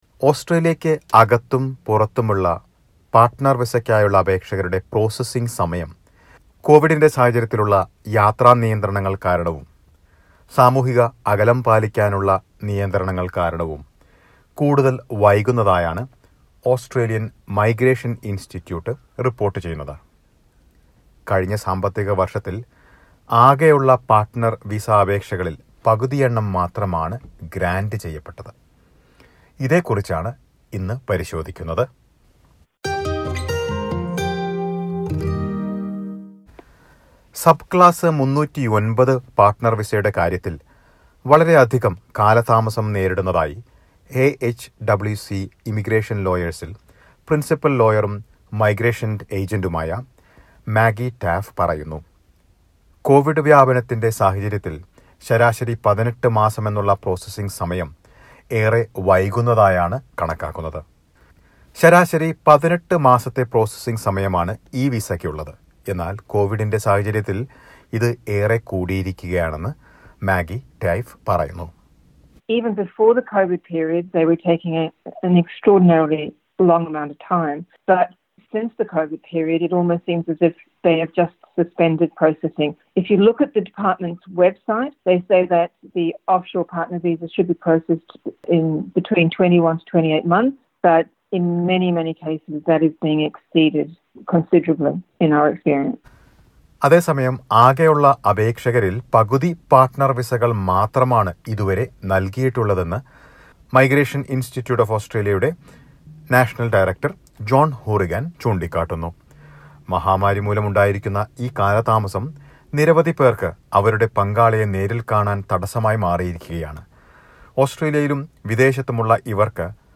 Travel restrictions and social distancing measures due to coronavirus outbreak are further delaying processing times for partner visa applicants within and outside Australia. Listen to a report.